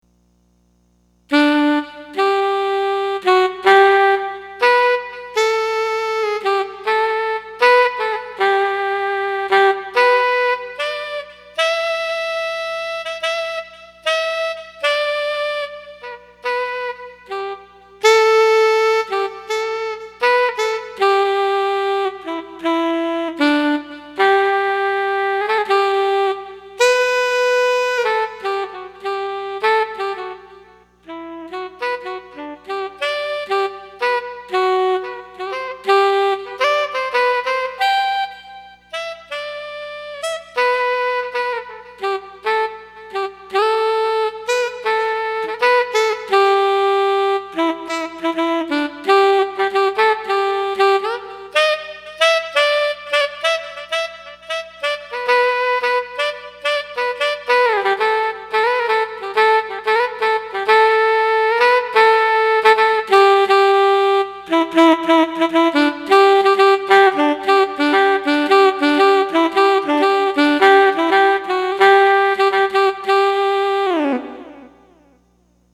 I composed all of these pieces and played all guitars. Drums, keyboards, and some bass guitars I programmed via computer and some bass guitars are my playing an actual bass guitar.
A highly improvised rendition of Auld Langsine recorded with the new Godin ACS nylon string guitar through the new Roland GR-33.